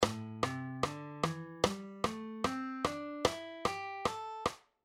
So, for example, in the key of A minor, the notes of the scale would be:
A-minor-Pentatonic-scale-how-it-sounds-like-1.mp3